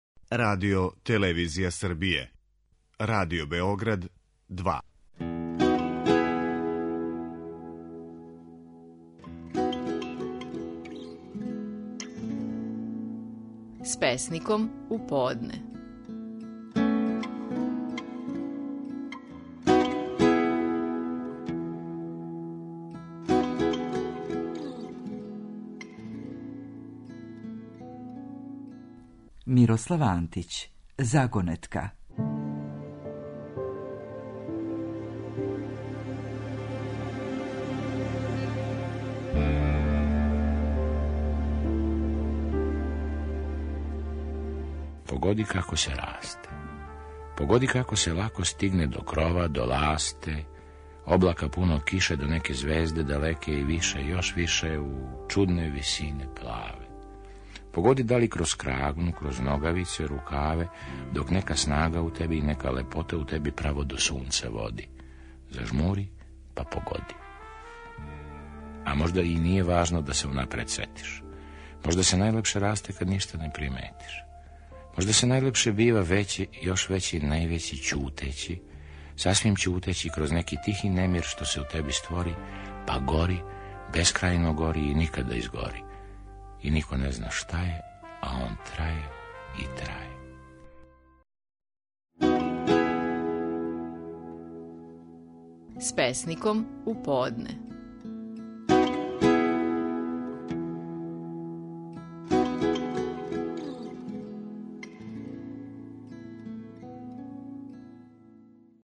Стихови наших најпознатијих песника, у интерпретацији аутора.
Мирослав Антић говори песму „Загонетка".